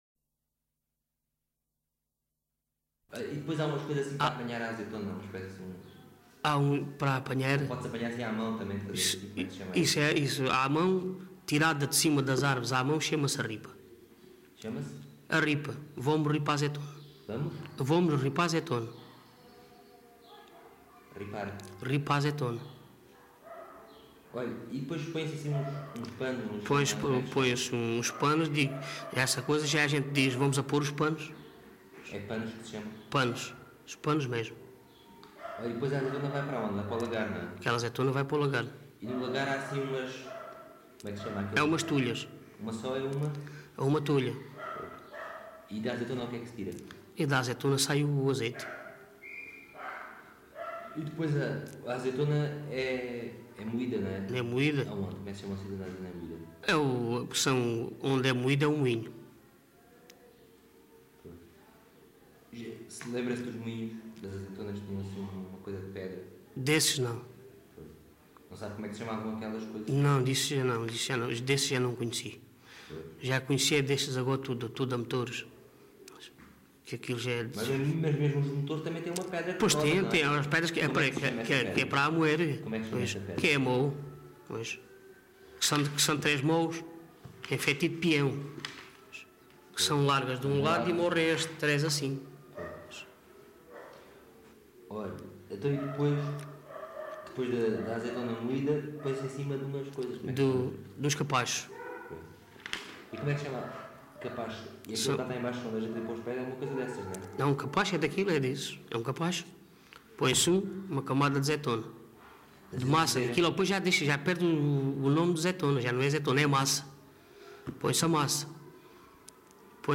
LocalidadeSerpa (Serpa, Beja)